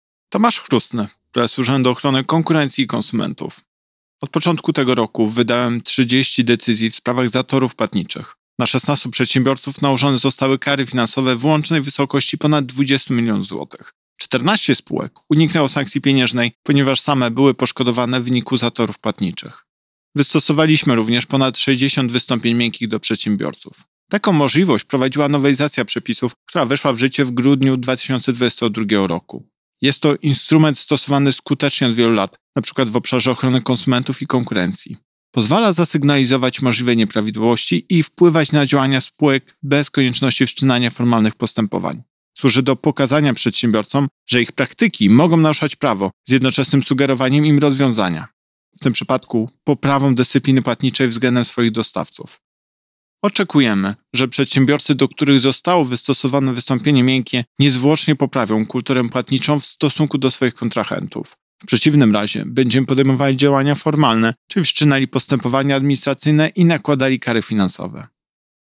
Pobierz wypowiedź Prezesa UOKiK Tomasza Chróstnego